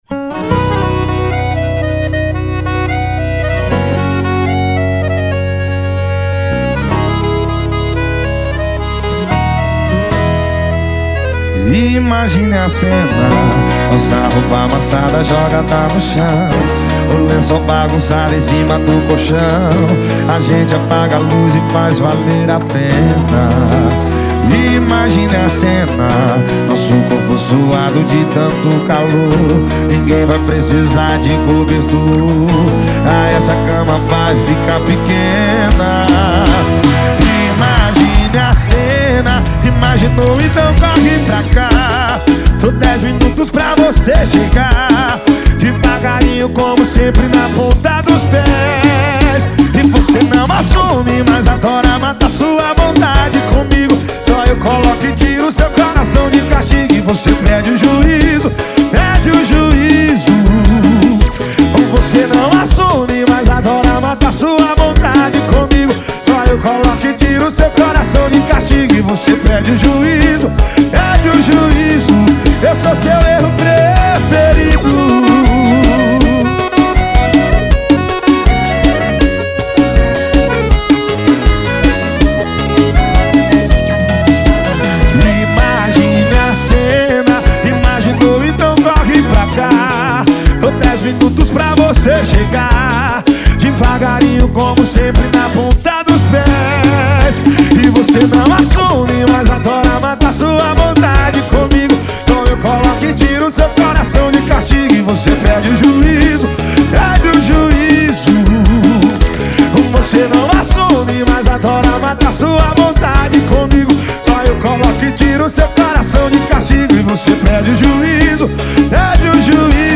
2024-02-14 18:37:05 Gênero: Forró Views